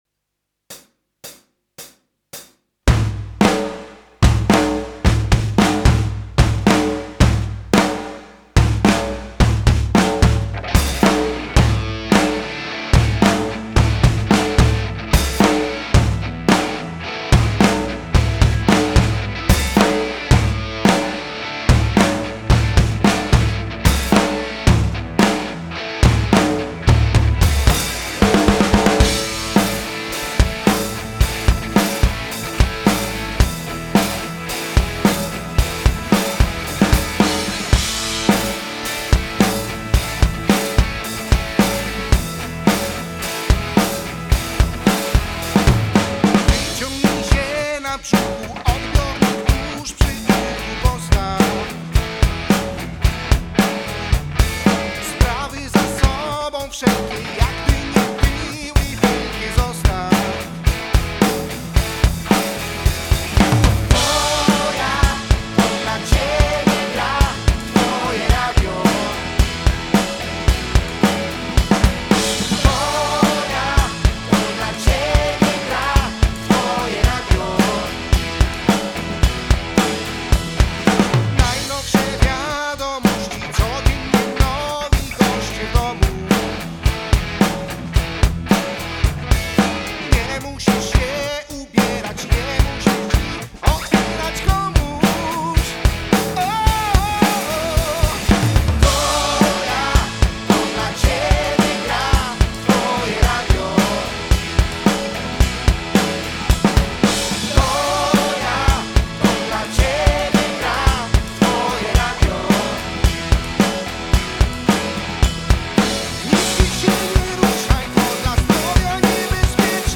Perkusja – Największy Zespół ROCKowy
drums.mp3